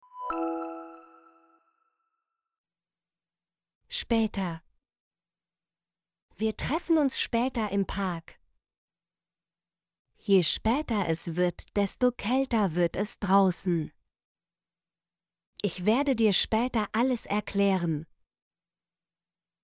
später - Example Sentences & Pronunciation, German Frequency List